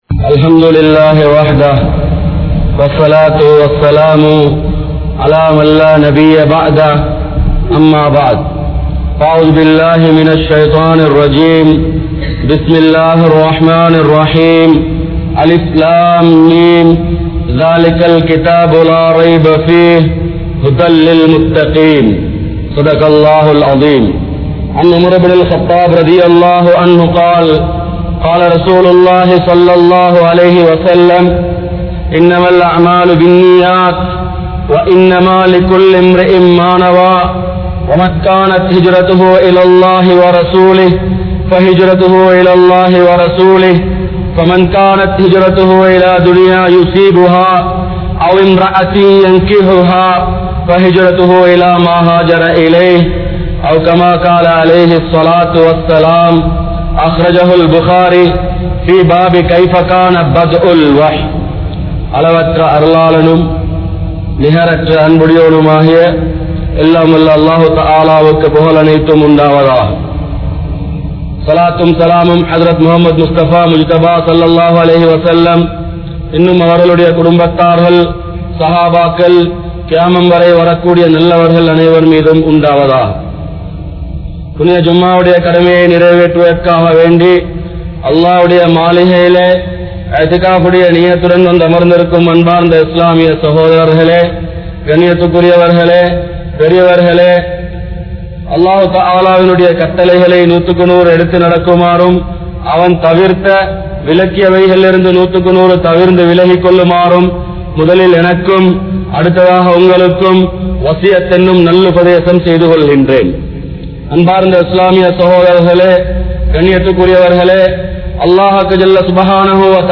Kollupitty Jumua Masjith